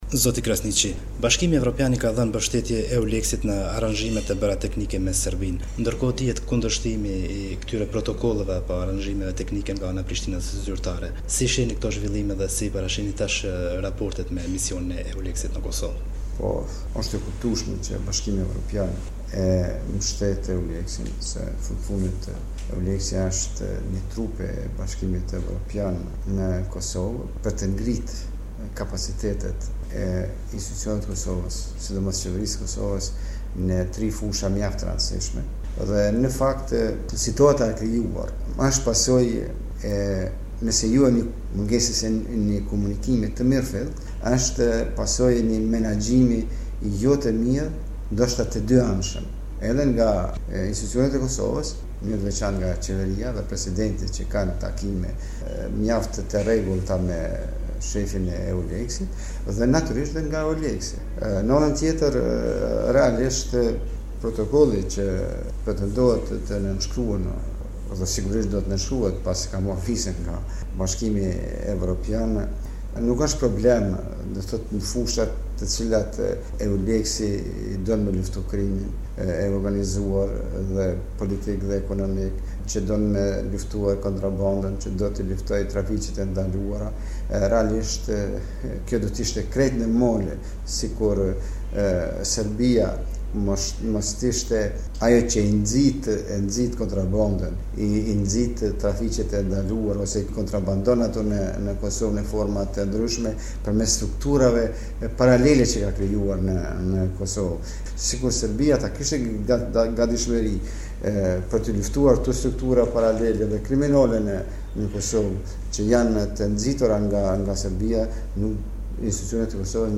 Thekse nga intervista